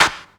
Index of /90_sSampleCDs/Best Service Dance Mega Drums/CLAPS HOU 1B